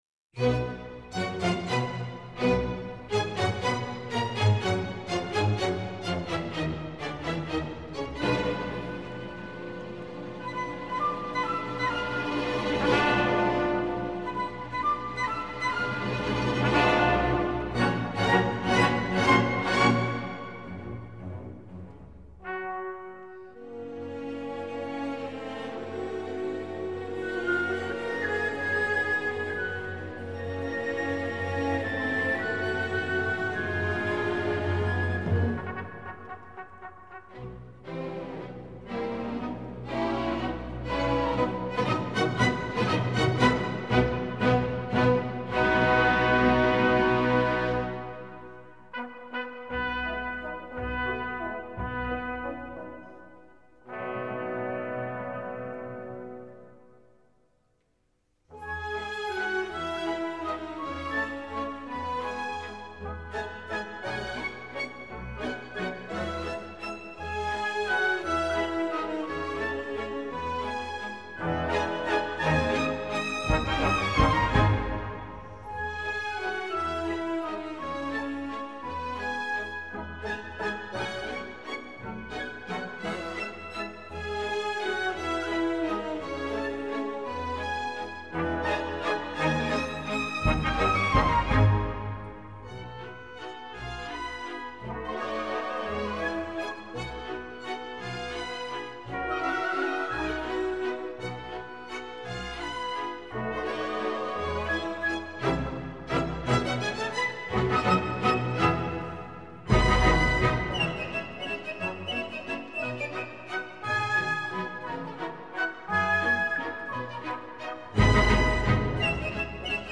Johann Jr. Strauss- Die jungen Wiener (Young Viennese) Waltz